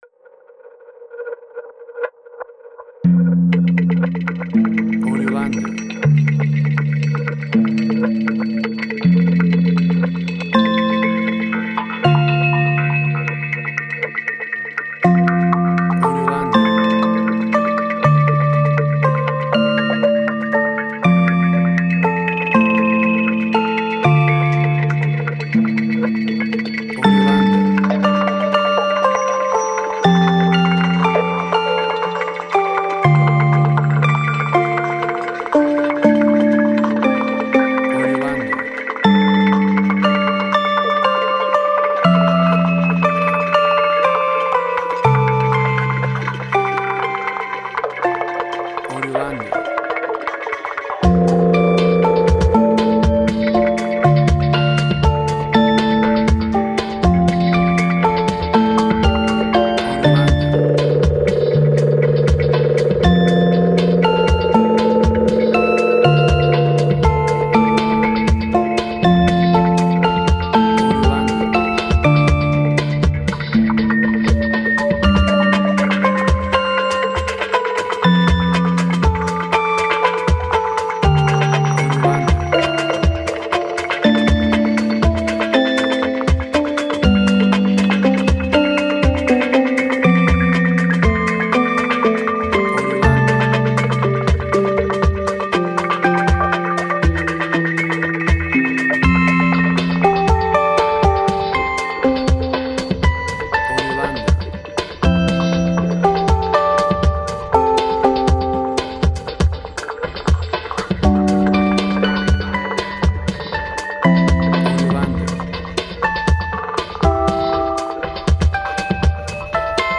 IDM, Glitch.
Tempo (BPM): 120